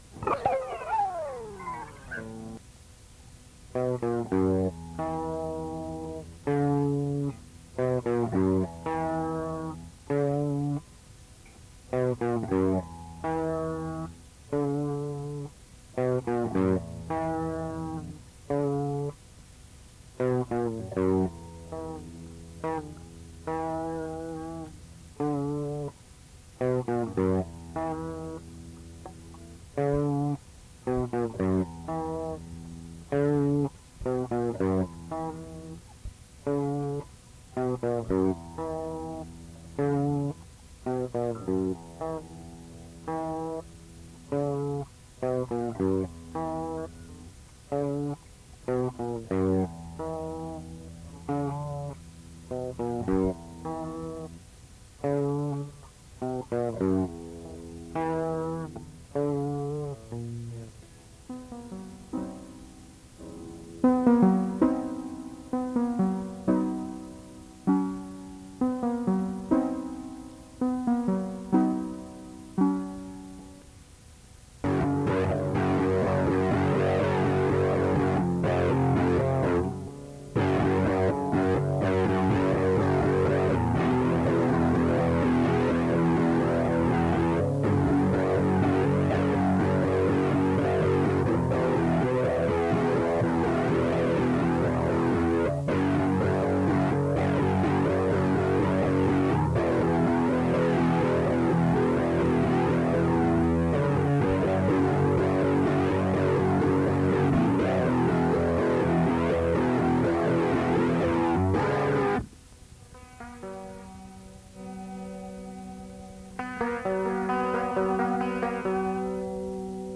unlistenable-garbage-1991.mp3